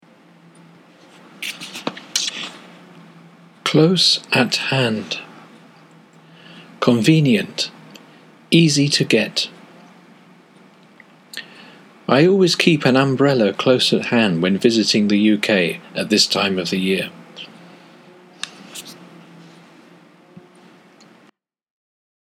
close at hand とは、一言でいえば 「近くにある」 ということですが、本日の例文のように物理的に何かの物が、すぐ手の届く所にあるという意味と、 時間的に差し迫っている、目前である、という意味があります。 英語ネイティブによる発音は下記のリンクをクリックしてください。